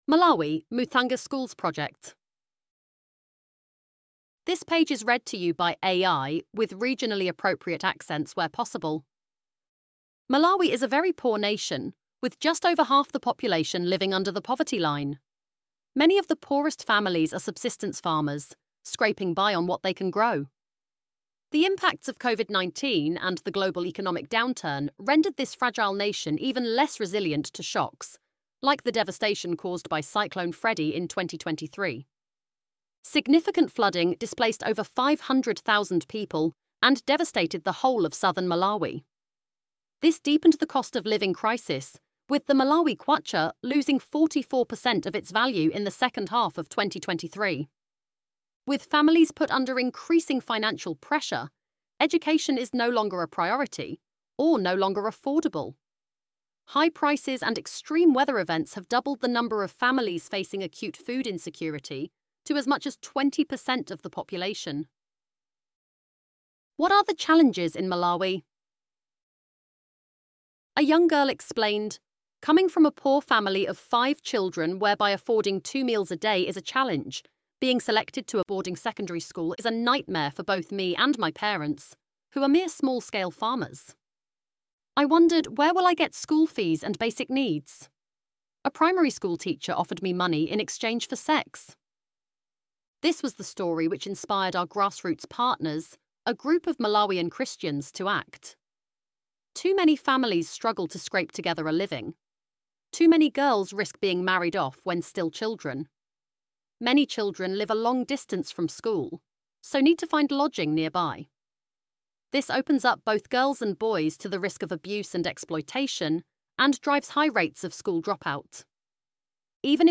ElevenLabs_MalMuthange_Schools_Project.mp3